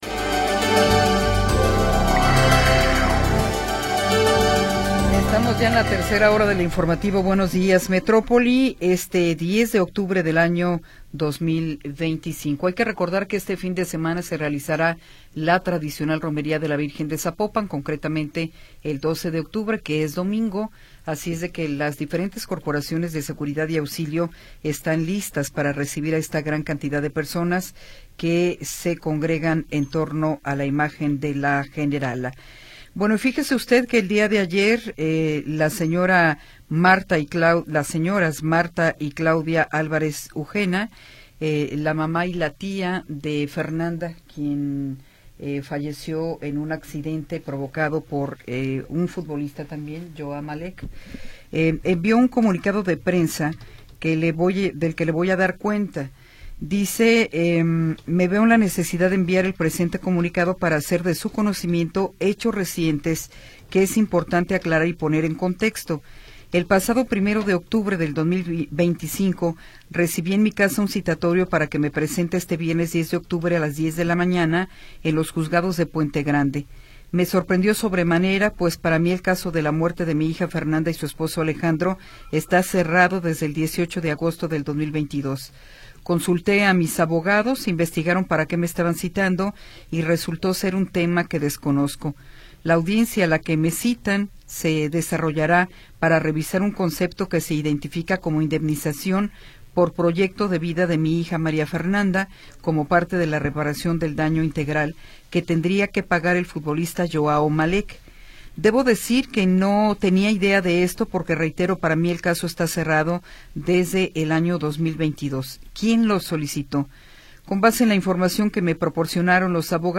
Buenos Días Metrópoli 3ra Hora - Notisistema Author: Notisistema Language: es-mx Genres: News Contact email: Get it Feed URL: Get it iTunes ID: Get it Get all podcast data Listen Now...